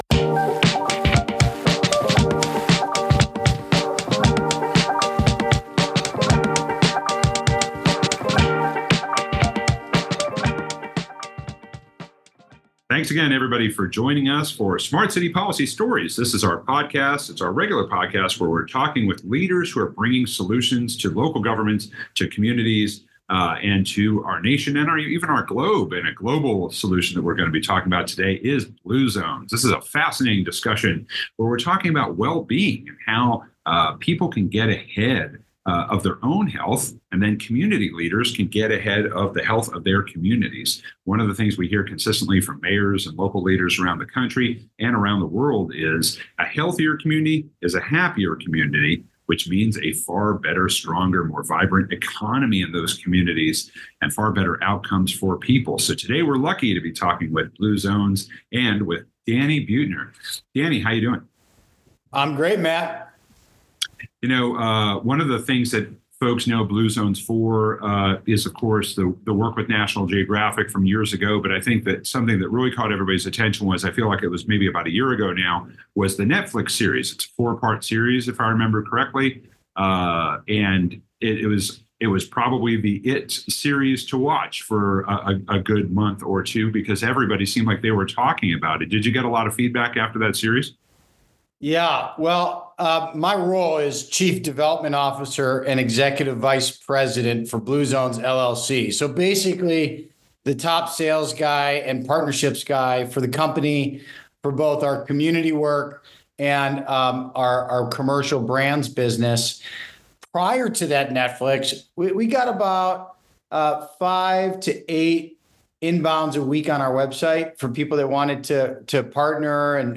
Interviews Danny Buettner: Health, Community, and City Planning - the BlueZones solution.